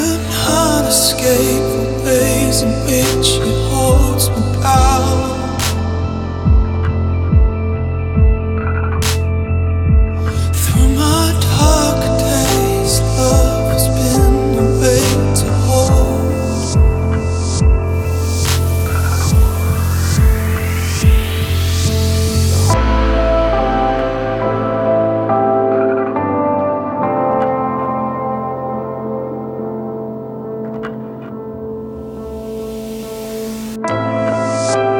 # Adult Contemporary